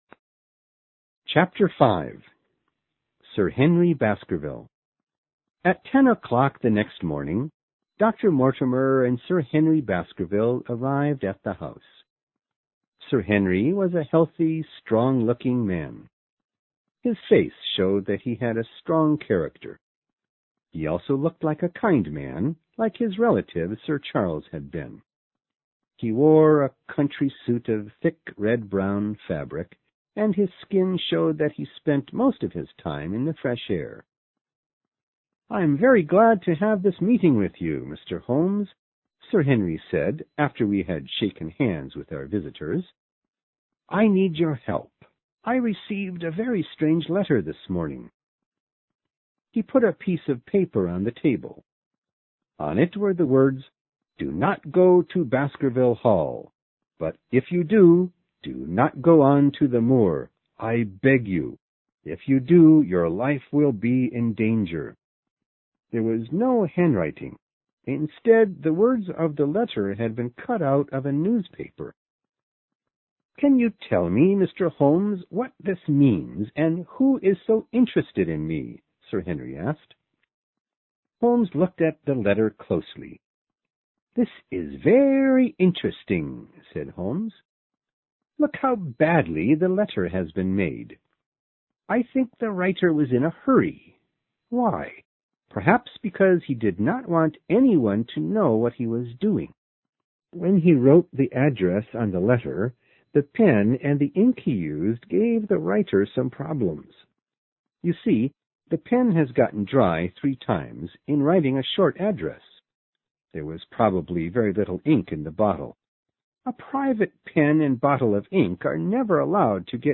有声名著之巴斯克维尔猎犬chapter5 听力文件下载—在线英语听力室